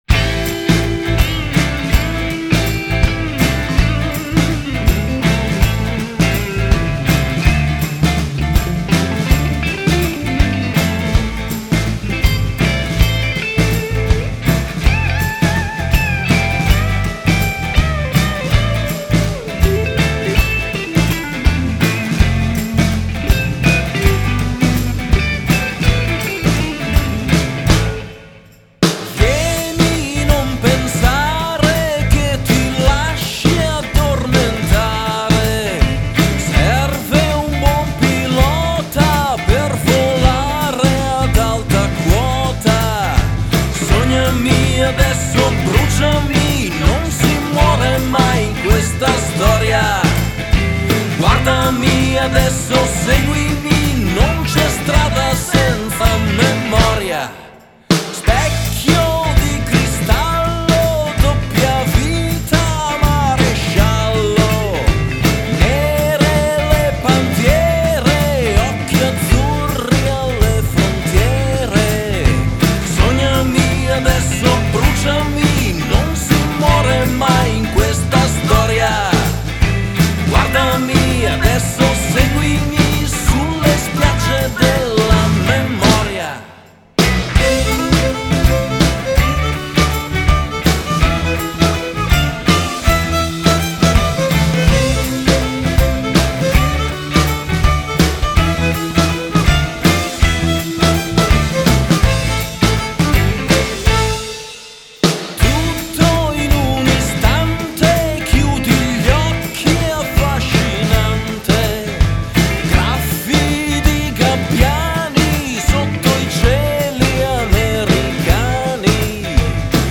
rock band, rock italiano